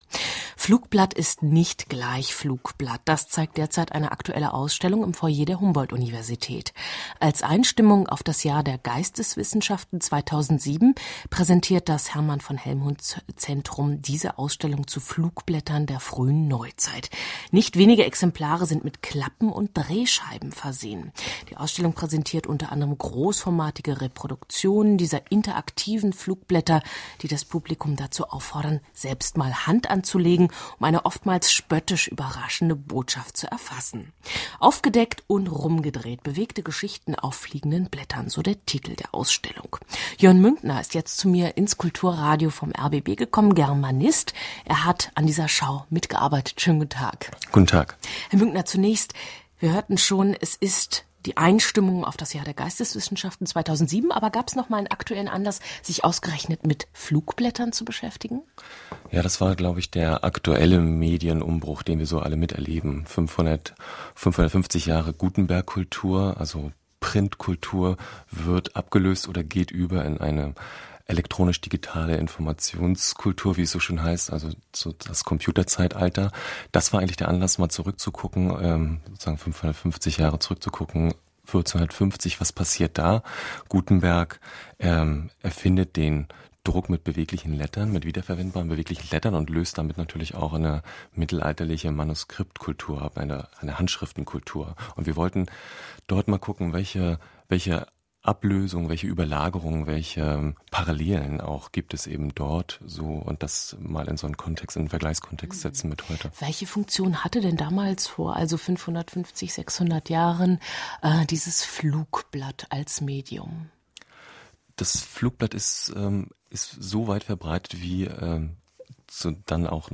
Kulturradio rbb, Beitrag vom 20.12.2006; Bericht zur Ausstellung: Aufgedeckt und rumgedreht